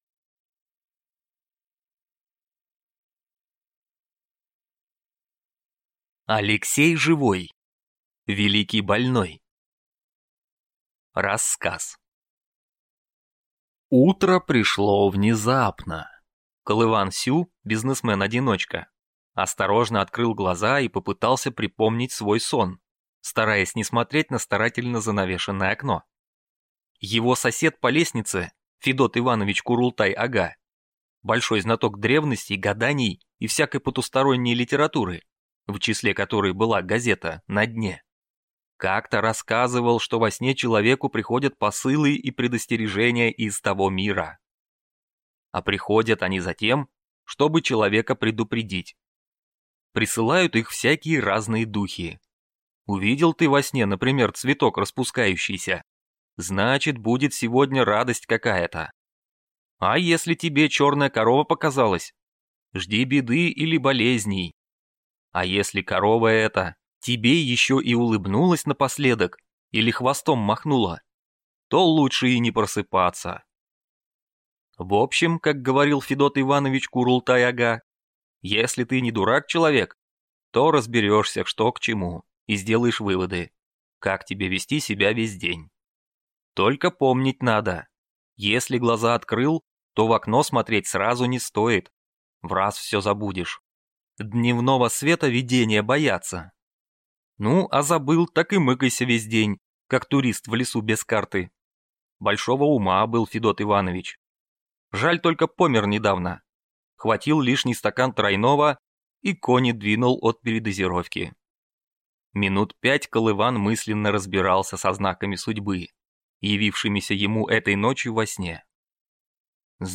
Аудиокнига Великий больной | Библиотека аудиокниг